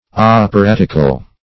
Operatical \Op`er*at"ic*al\